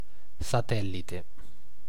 Ääntäminen
France: IPA: [la lyːn]